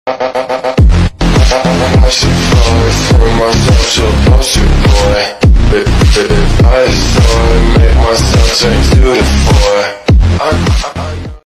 sorry for the bad quality💔